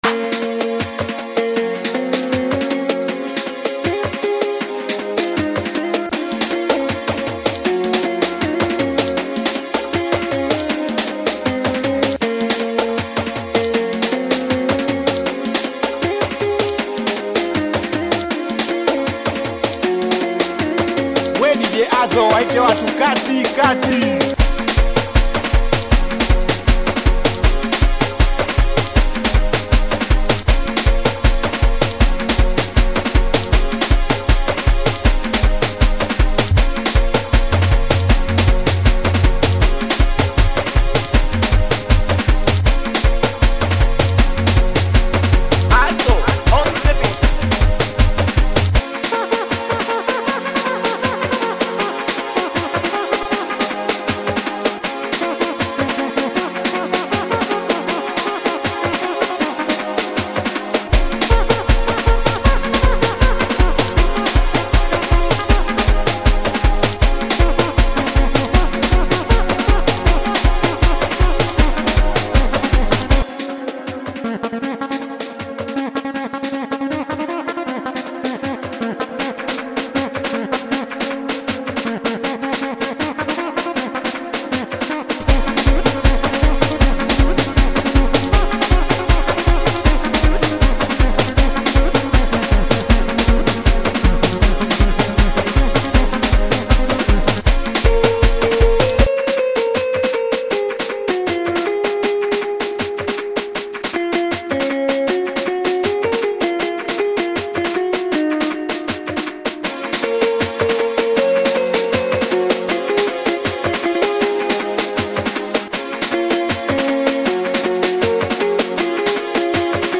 DOWNLOAD BEAT SINGELI